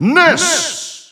Announcer pronouncing Ness's name in Dutch.
Ness_Dutch_Announcer_SSBU.wav